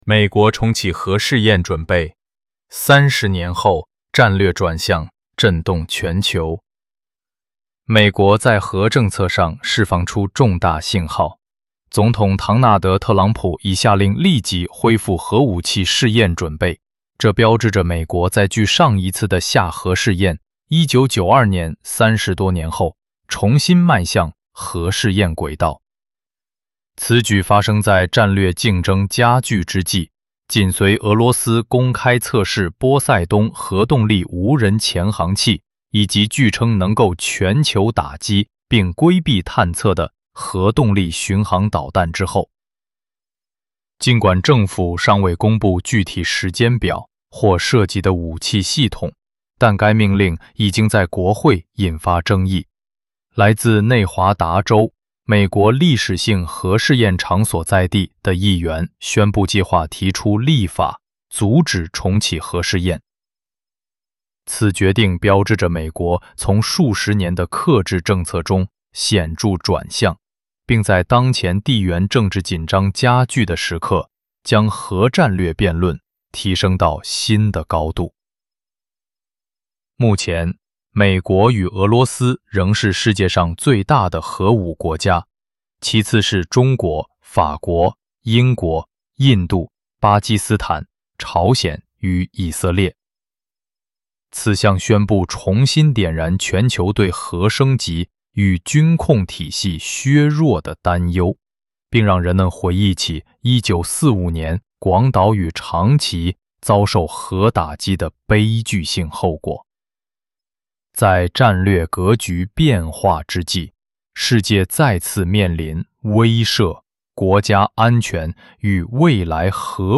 Mandarin Audio Tribute • Solemn Edition
Dick_Cheney_In_Memoriam_TributeChino.mp3